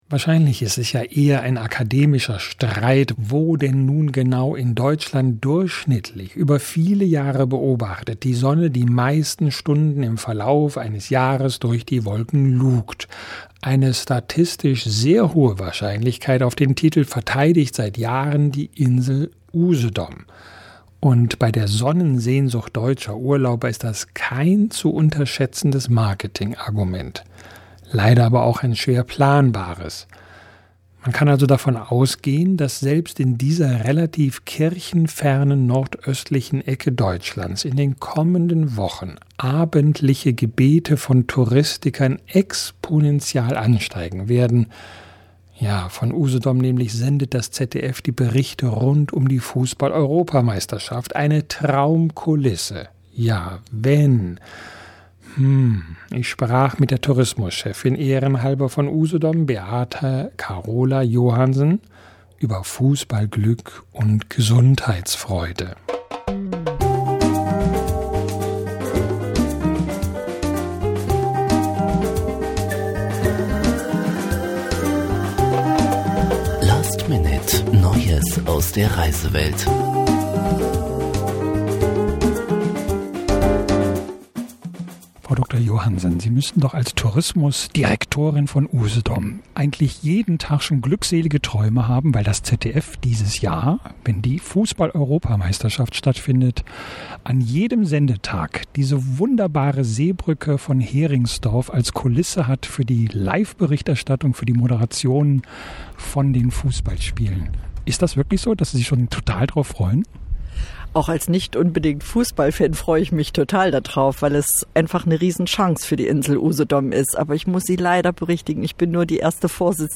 Direktlink: Gespräch